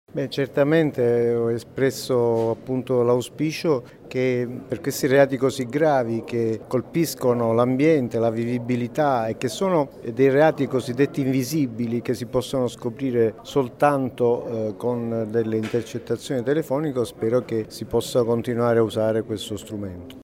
29 apr. – Il Procuratore nazionale antimafia, Piero Grasso, interviene nel dibattito e nelle polemiche che riguardano il ddl sulle intercettazioni telefoniche e ambientali in discussione in Senato. Lo ha fatto con decisione, nel corso del convegno che stamattina si è svolto all’Archiginnasio sui temi della gestione dei rifiuti, “tra realtà industriale ed ecomafia”.
Ascolta Pietro Grasso